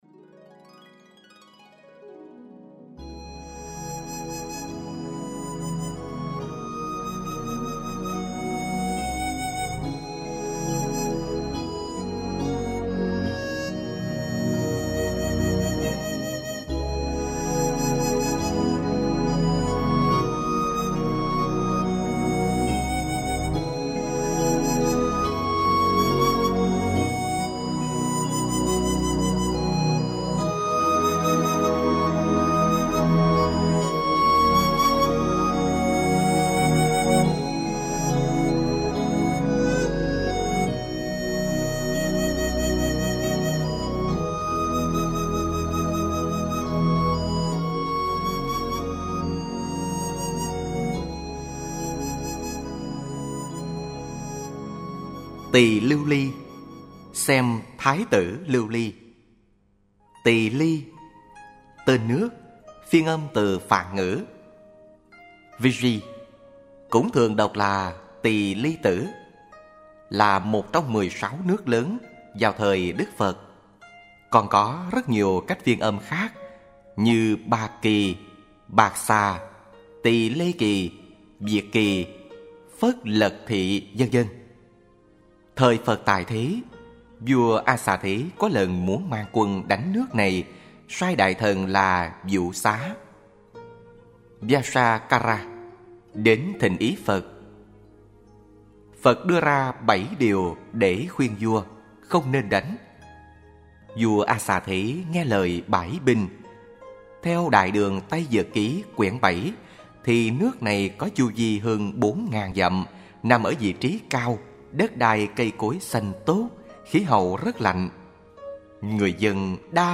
Kinh Tụng: Kinh Diệu Pháp Liên Hoa Khai Kinh Diệu Pháp Liên Hoa 1.